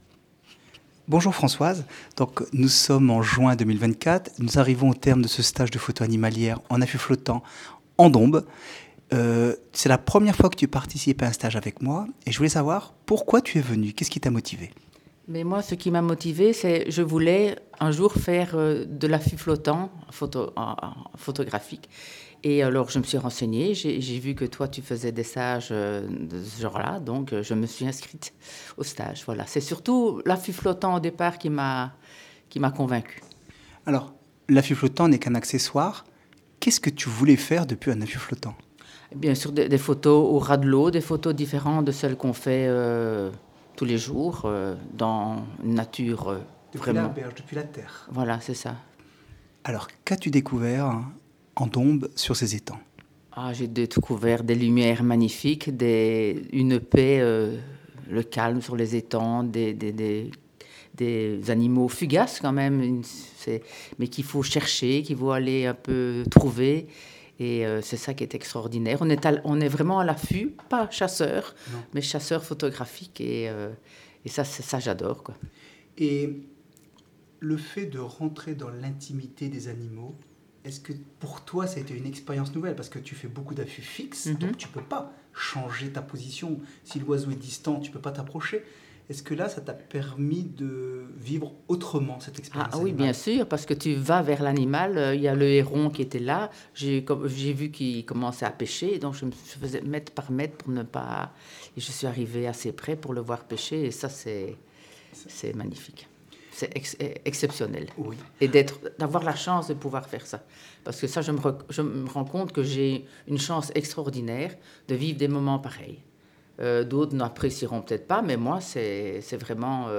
Le commentaire oral des participants